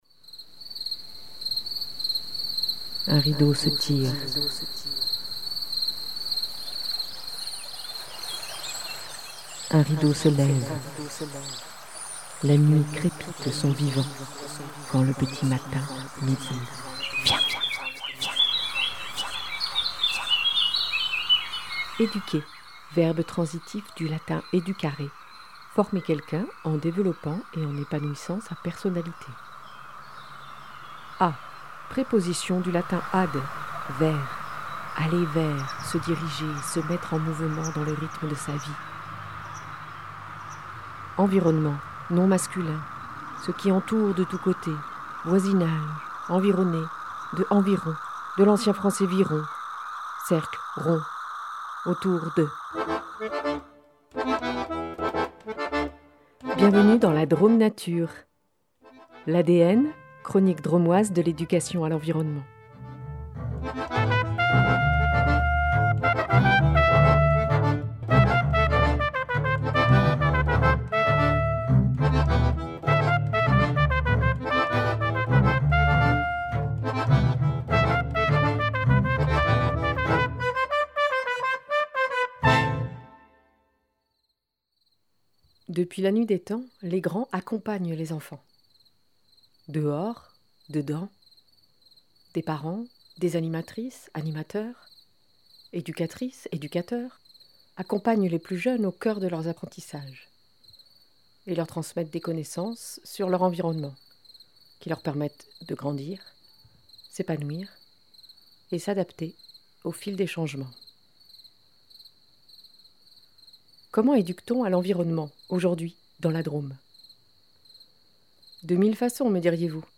Émission Drômoise de l’Éducation à l’Environnement.